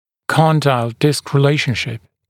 [ˈkɔndaɪl dɪsk rɪ’leɪʃnʃɪp] [-dɪl][ˈкондайл диск ри’лэйшншип] [-дил]соотношение диск-мыщелок